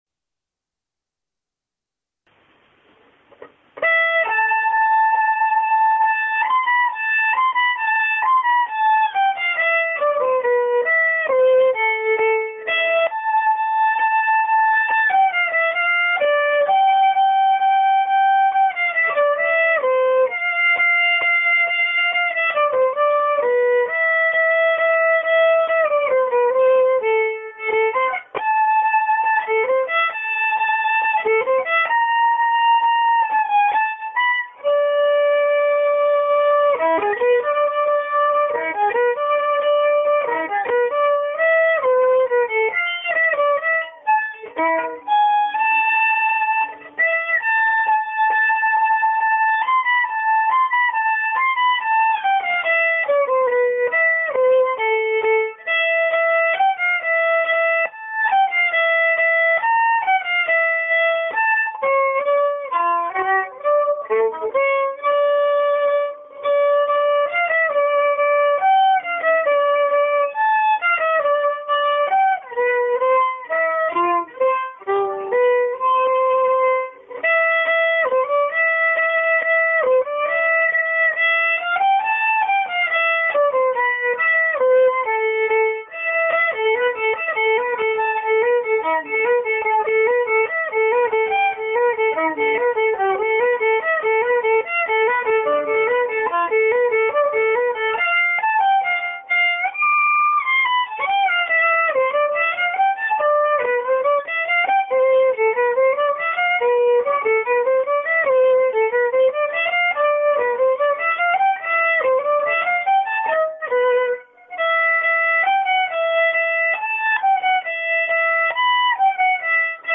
携帯のﾎﾞｲｽﾚｺｰﾀﾞｰでの録音であることと、
♪ｳﾞｨｳﾞｧﾙﾃﾞｨの協奏曲ｲ短調第一楽章(伴奏ﾅｼ
当初より随分速度アップして弾いたのですが
途中つっかえてるし(Ｔ。Ｔ）
それよりなにより、全体的にものっすごい走って聴こえるのは…
音程ももはやどこがどうと具体的に言えないくらいあっちこっち乱れまくり…